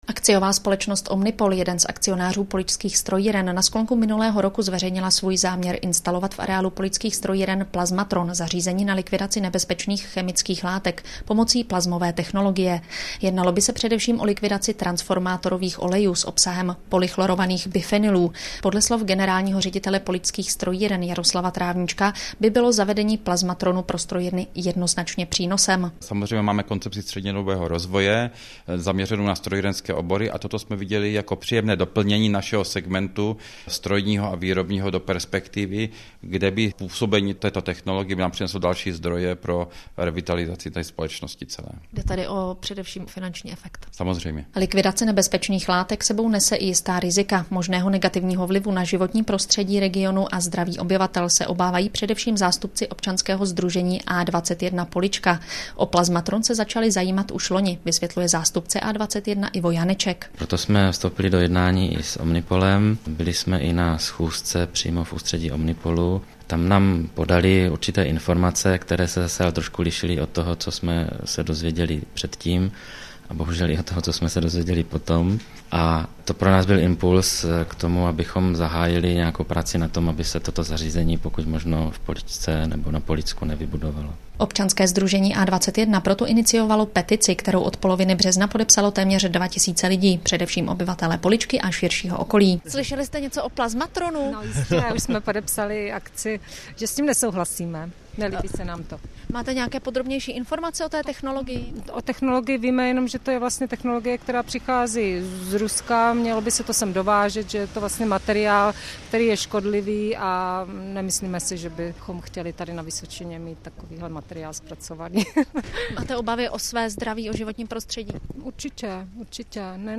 8.4.2004 - záznam z Českého rozhlasu Pardubice
z ranního vysílání (cca 1MB), z 11.15 hod (MP3 cca 1MB), z 16.15 hod (MP3 cca 1MB)
rozhlas.wav